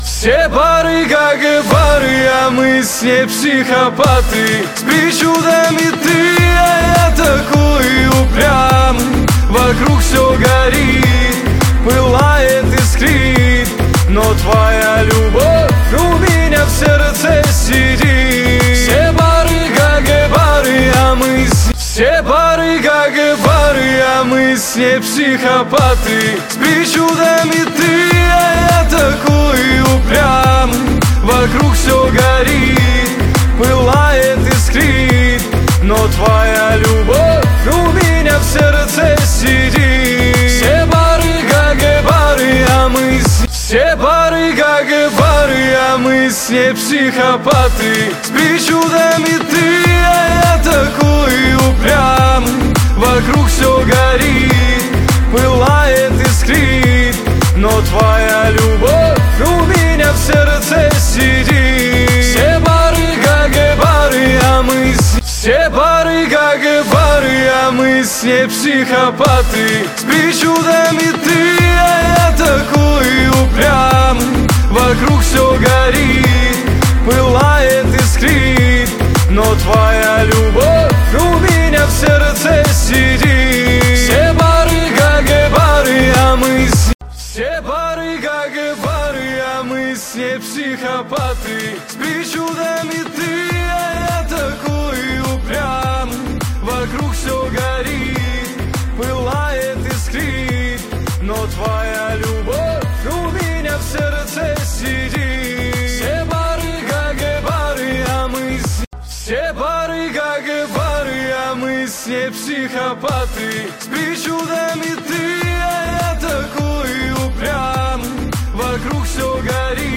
Качество: 320 kbps, stereo
Русские поп песни, Поп музыка